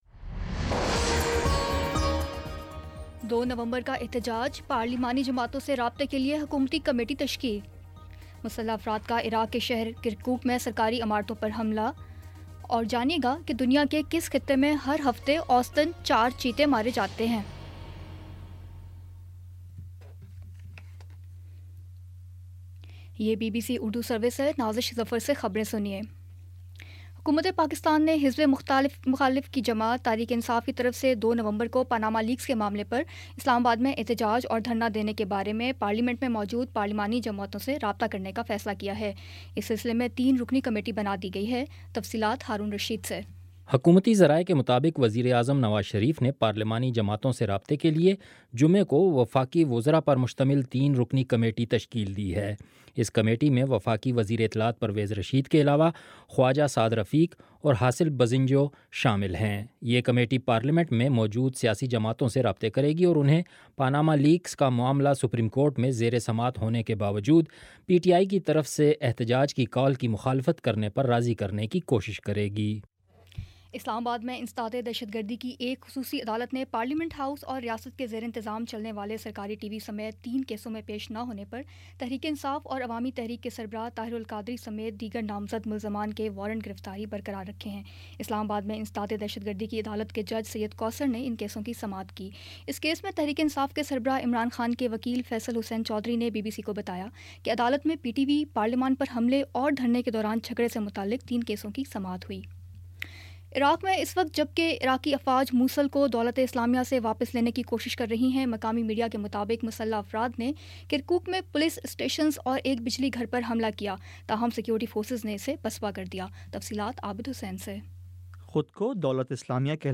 اکتوبر 21 : شام پانچ بجے کا نیوز بُلیٹن